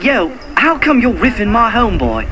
Note: All of these sounds are spoken by the cartoon characters, NOT the real guys, unless otherwise noted.
an NKOTB cartoon